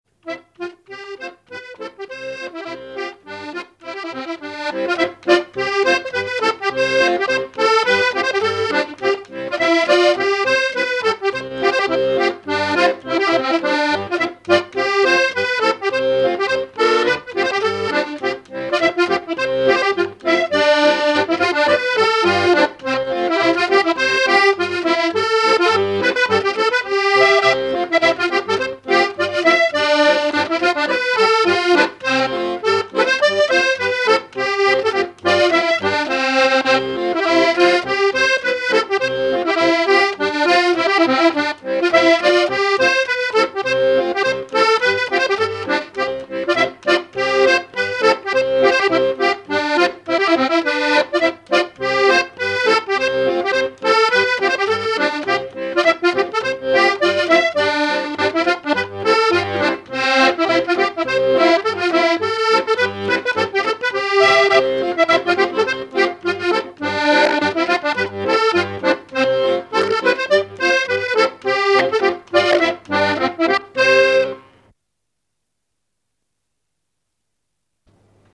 Enregistrement original de l'édition sur disque vinyle
musique pour les assauts de danse et le bal.
accordéon(s), accordéoniste ; musique traditionnelle
danse : scottich trois pas
Pièce musicale inédite